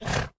sounds / mob / horse / donkey / idle1.mp3